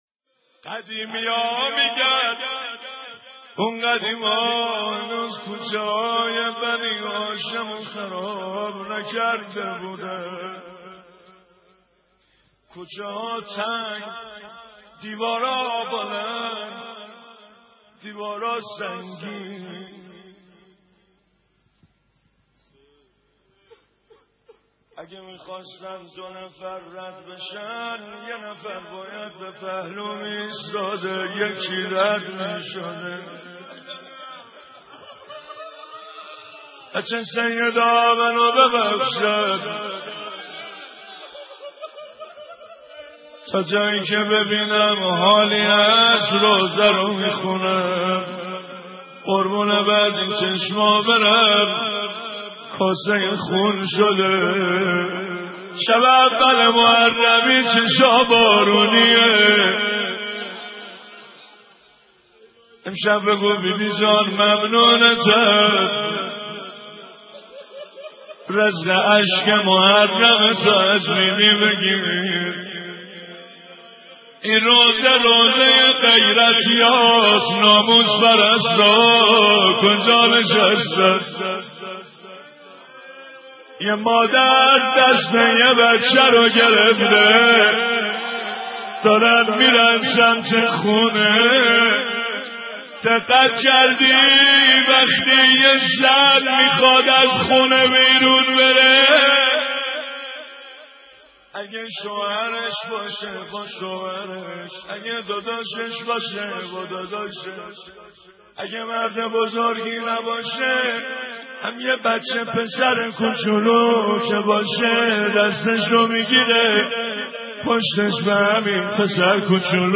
روضه حضرت زهرا (س)